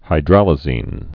(hī-drălə-zēn)